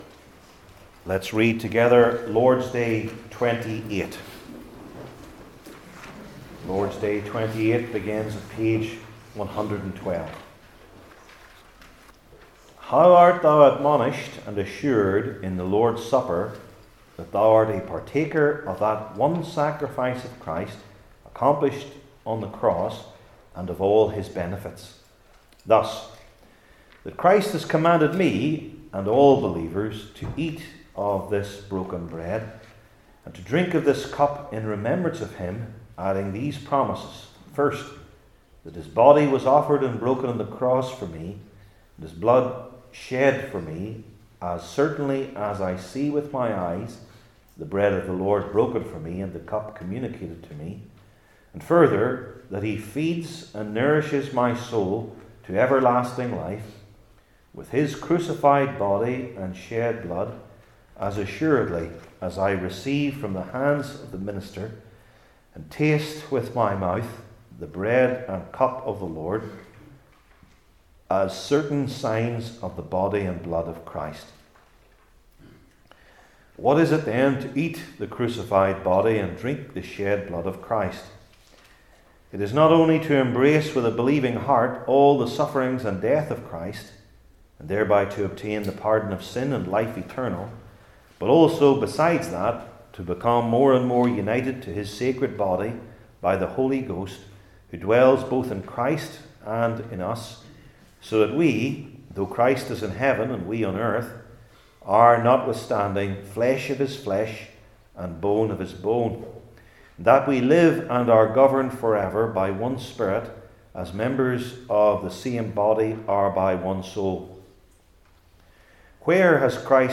Heidelberg Catechism Sermons